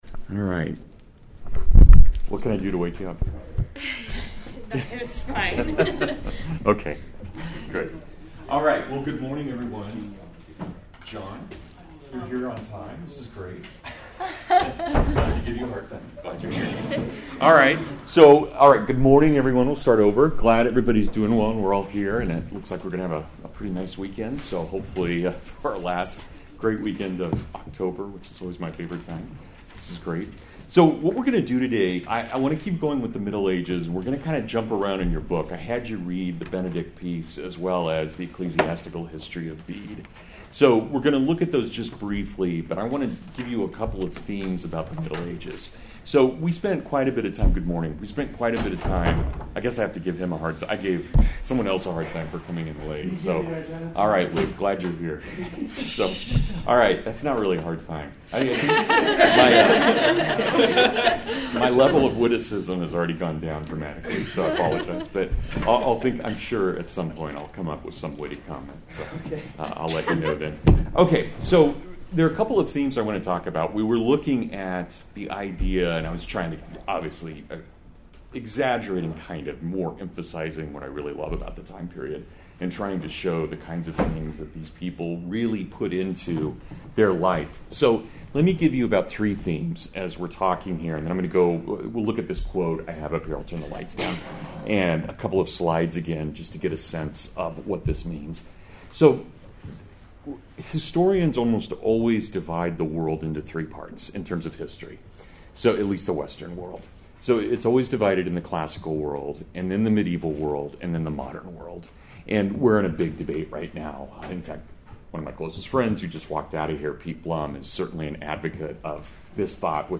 The Distinctiveness of Medieval Culture (Full Lecture) | Stormfields